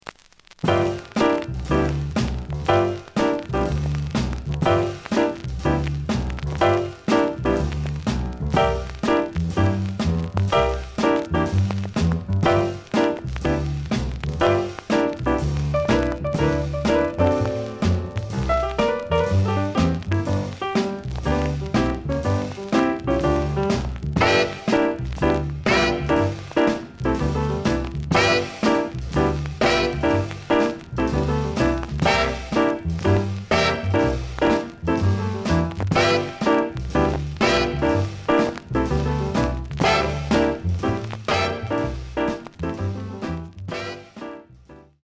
The listen sample is recorded from the actual item.
●Format: 7 inch
●Genre: Rhythm And Blues / Rock 'n' Roll